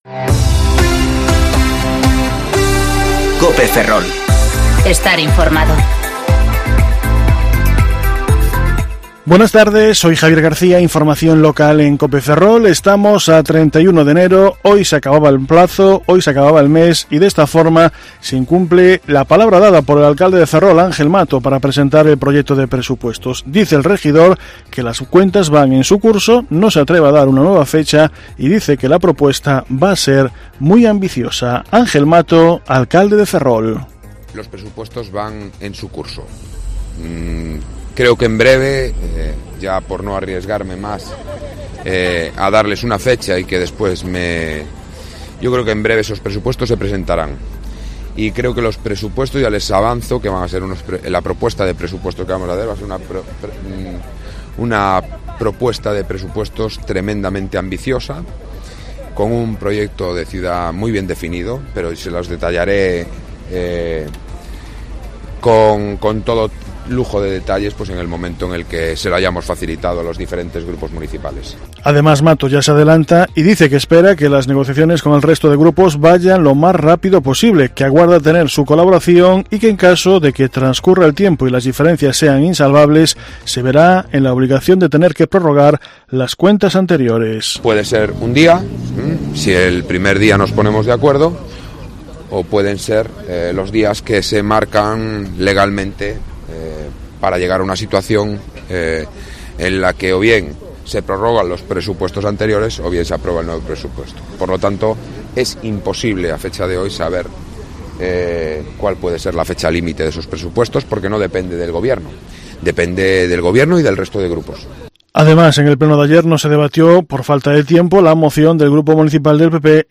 Informativo Mediodía COPE Ferrol - 31/1/2020 (De 14,20 a 14,30 horas)